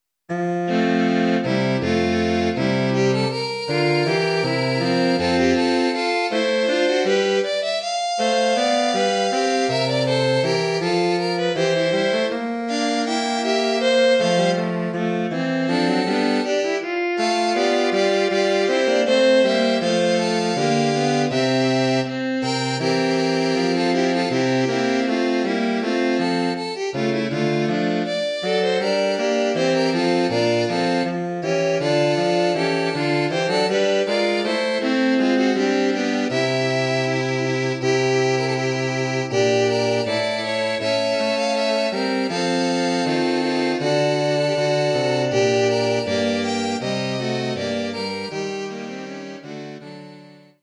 Bearbeitung für Streichquartett
Besetzung: 2 Violinen, Viola, Violoncello
arrangement for string quartet
Instrumentation: 2 violins, viola, violoncello